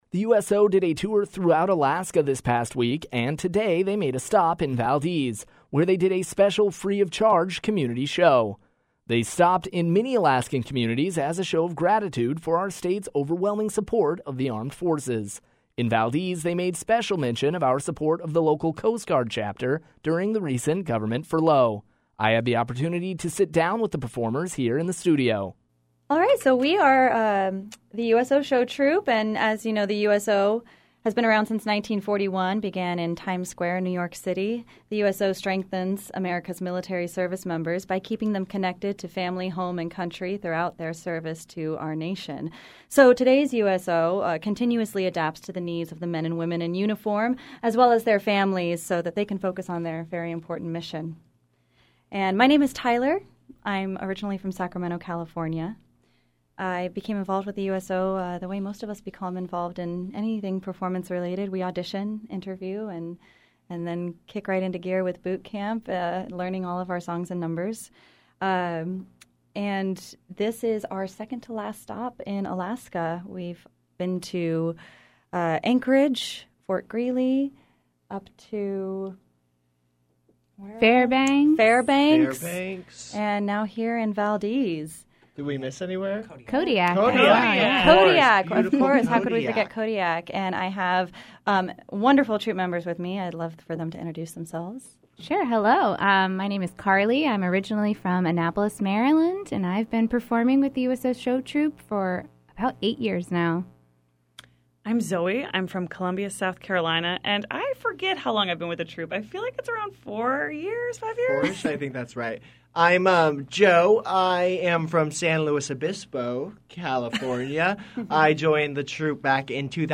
USO-interview-final.mp3